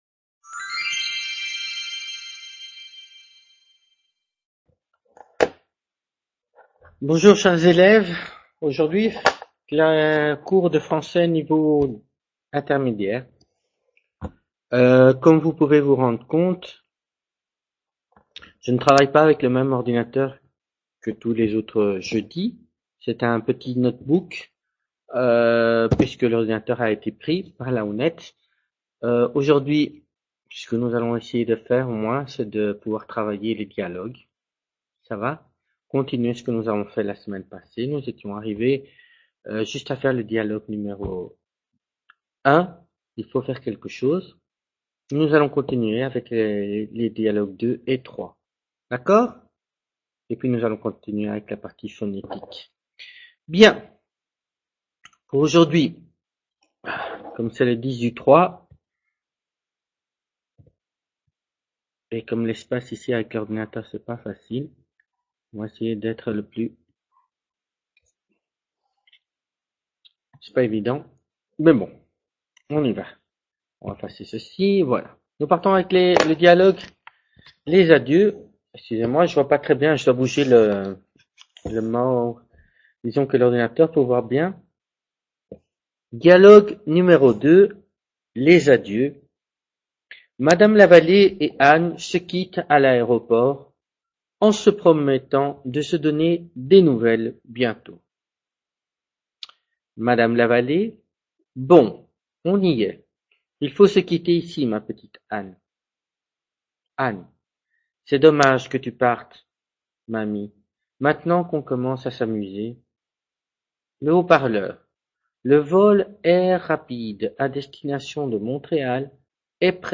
Clase de Francés Nivel Intermedio 10/03/2016 | Repositorio Digital